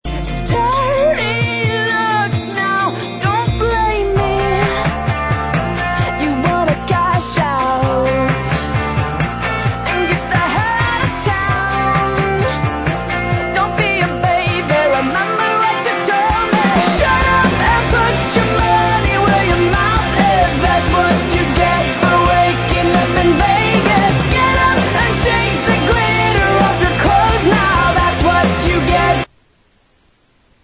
• Rock Ringtones